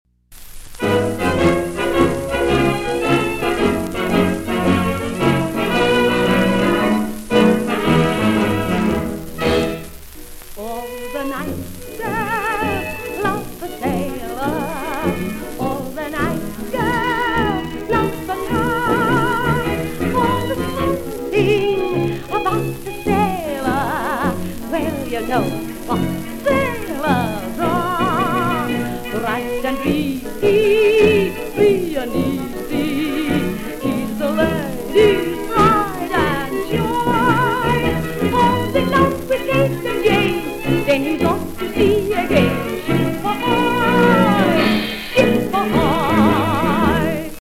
1930 medley of hits